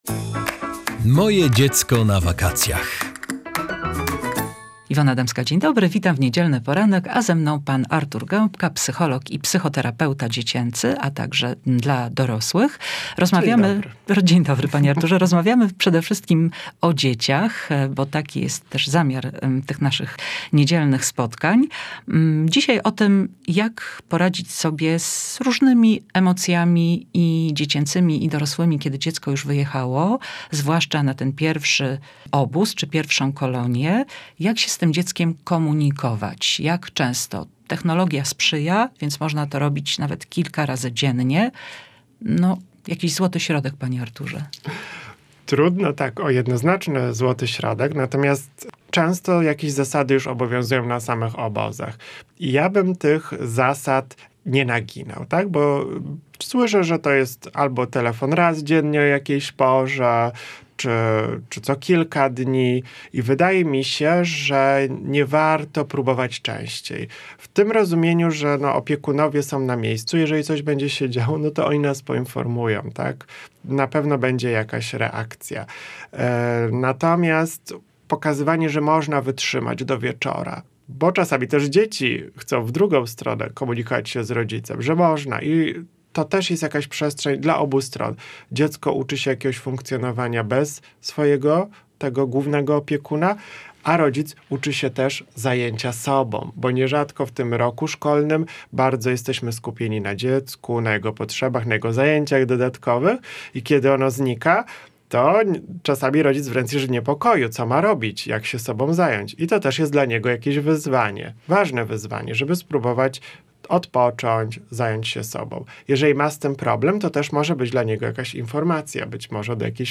O tym w audycji